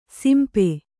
♪ simpe